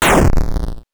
8 bits Elements
explosion_21.wav